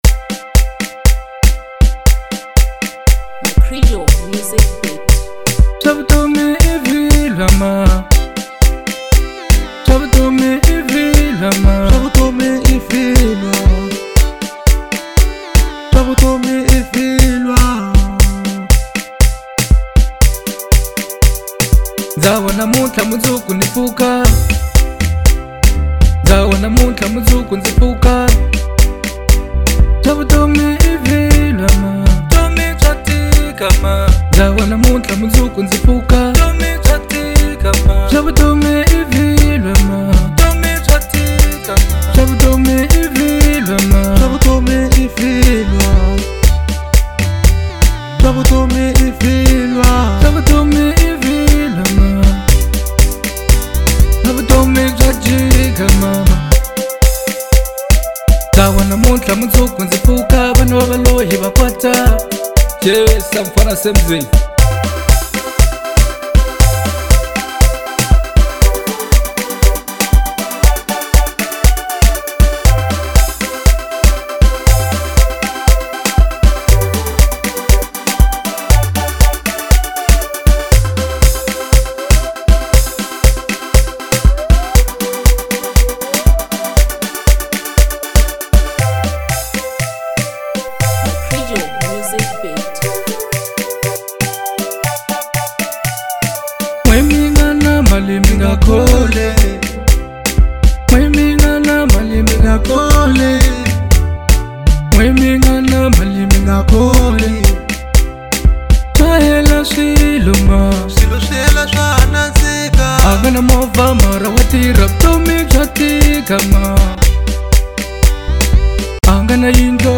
05:04 Genre : Bolo House Size